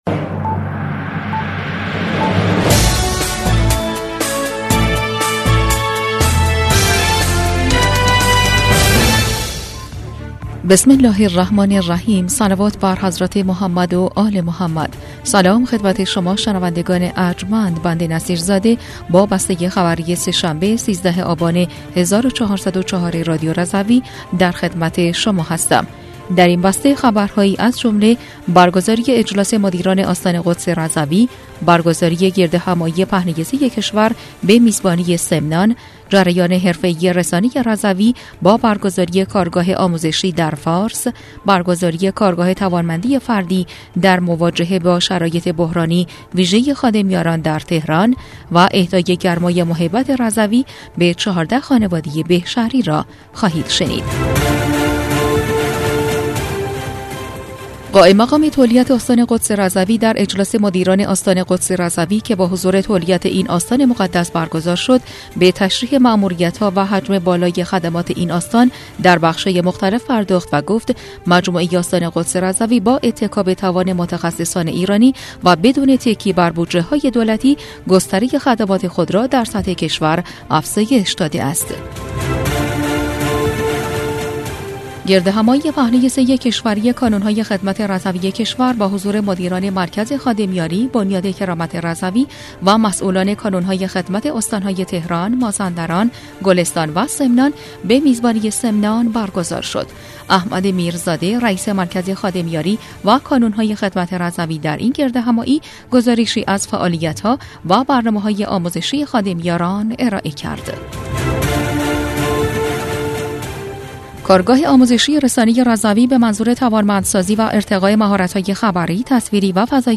بسته خبری ۱۳ آبان ۱۴۰۴ رادیو رضوی؛